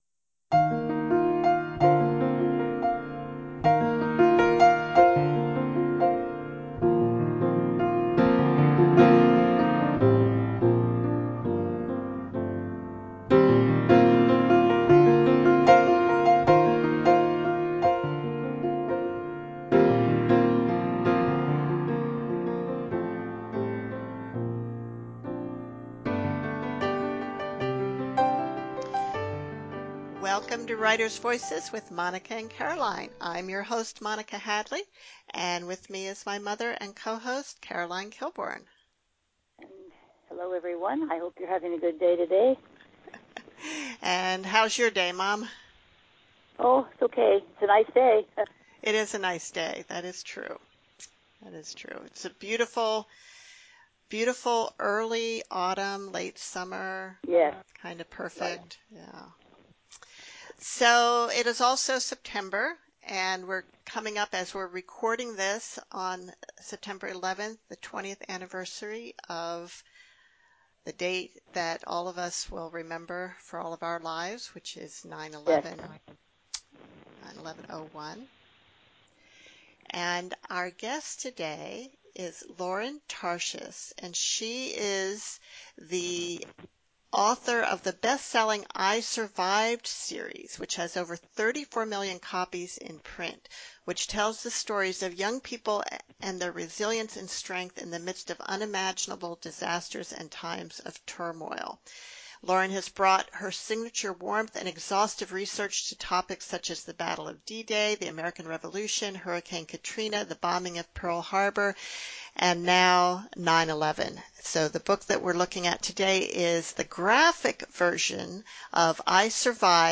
Writers Voices talks with Lauren Tarshis about her new book - I survived the attacks of September 11th, 2001